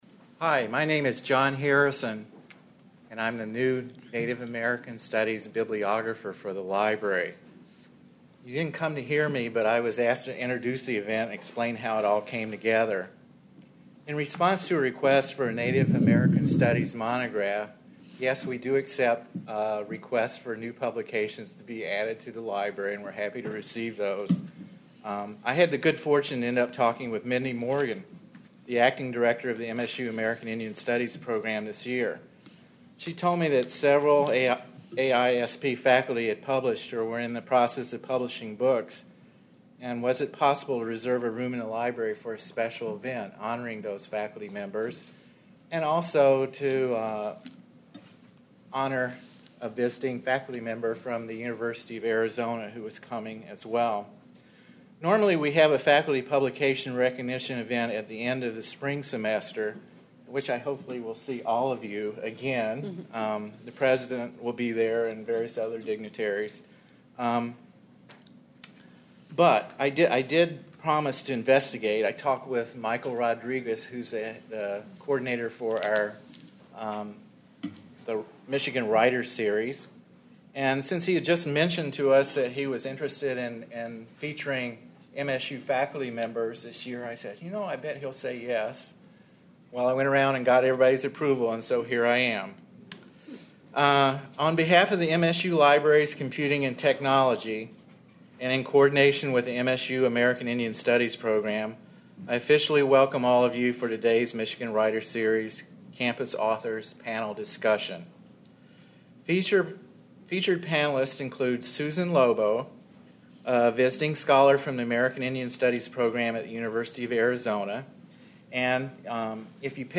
A panel discussion featuring Michigan State University faculty discussing publishing books on Native American history and culture